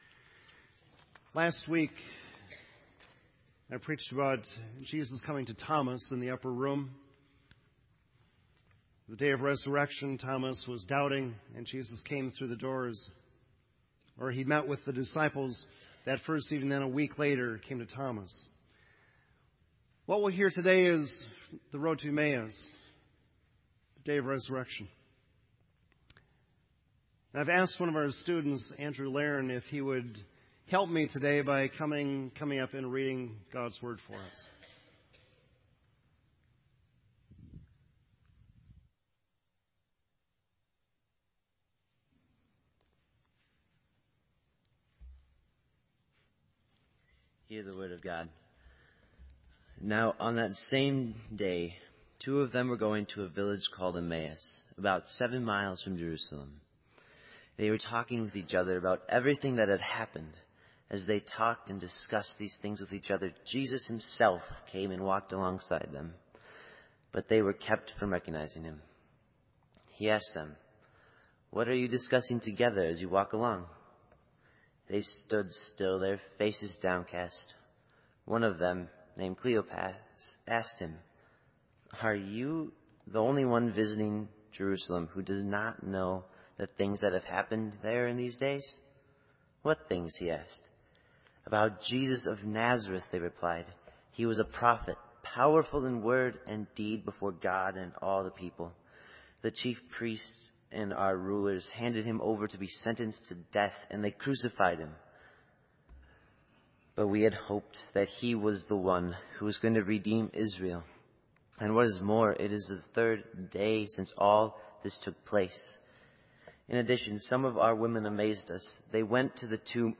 Genre: Sermon.